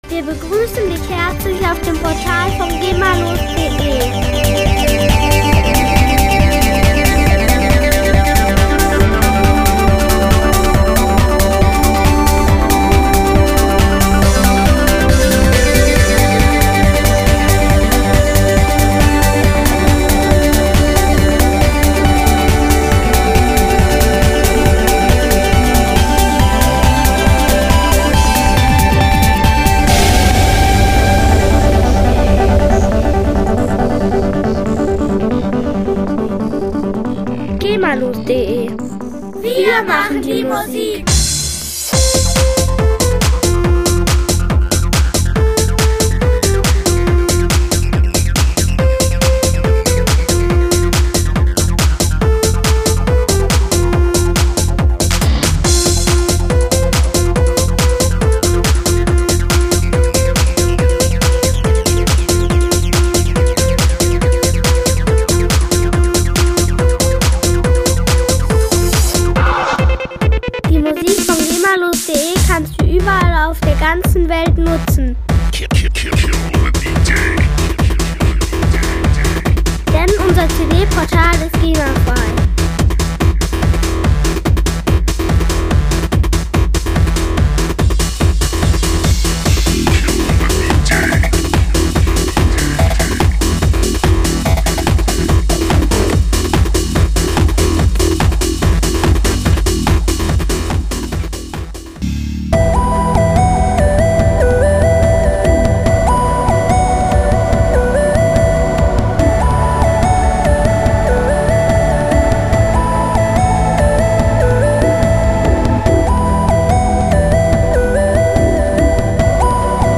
Musikstil: Psytrance & Goa
Hörprobe [4.825 KB]